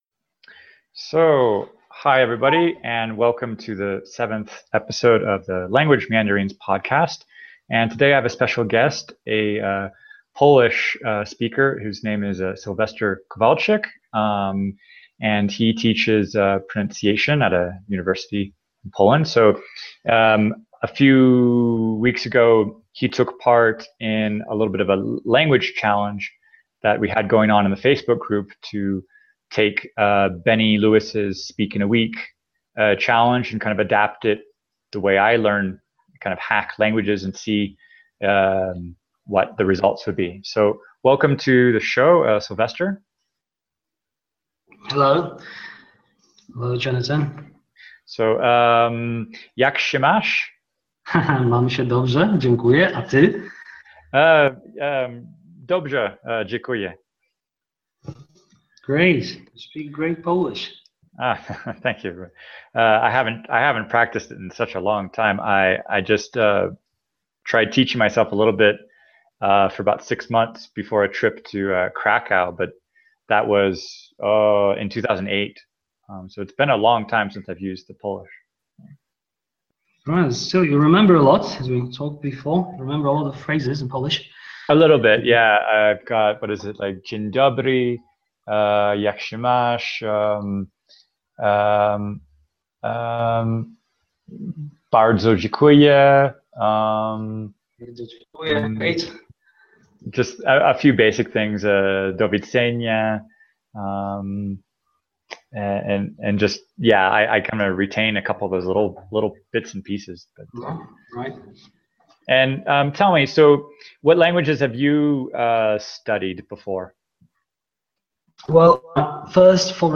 Polish, Ukrainian, Russian interview